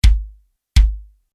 Drumset-Mikrofonierung
Position 3: ... mit gerade in die Bassdrum hineinragender Mikrofonkapsel
So kannst Du eine Klang-Kombination aus den beiden anderen Positionen schaffen.